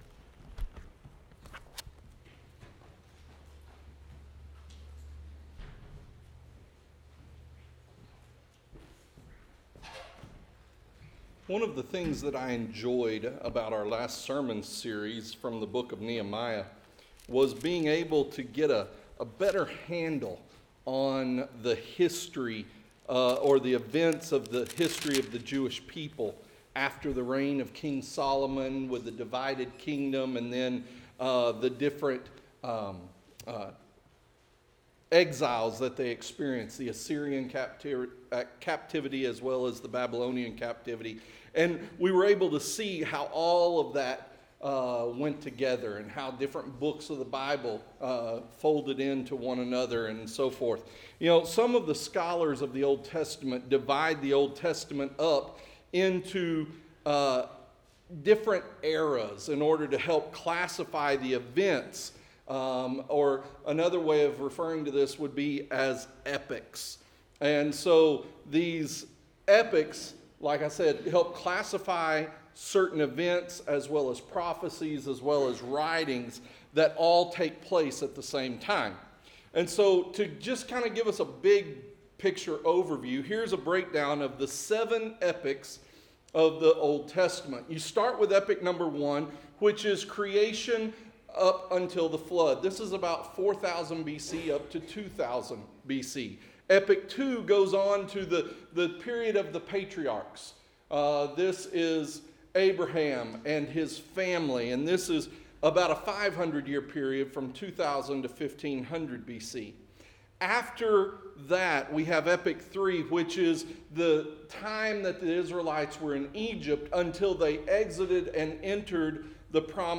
Interactive Sermon Notes Series: LUKE-In the Steps of the Savior , LUKE-The Prelude to Christ's Ministry